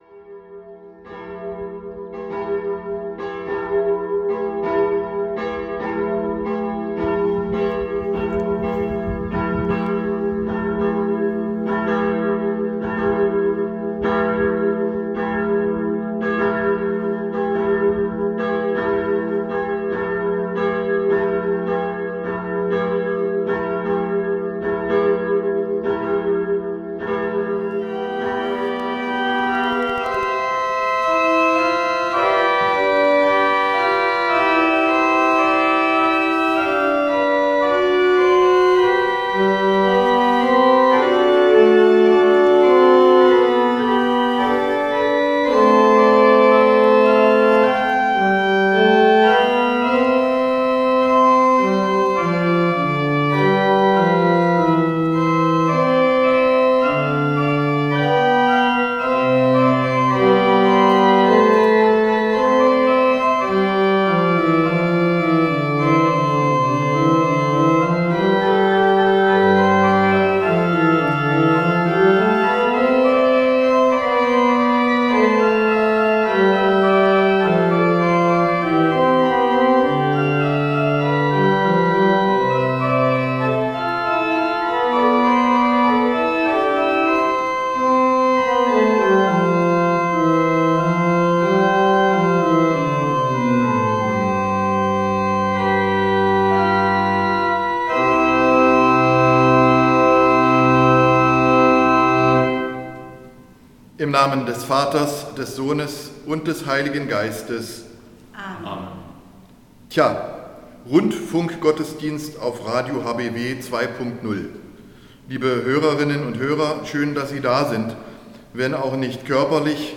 Radiogottesdienst
Wir bringen Ihnen die Kirche nach Hause. Nach dem großen Zuspruch beim Ostergottesdienst hat sich der Evangelische Kirchenkreis Bernburg dazu entschieden, jede Woche einen neuen Gottesdienst in einer Kirche in der Region zu gestalten. radio hbw sendet die Gottesdienste immer am Sonntag ab etwa 10:30 Uhr.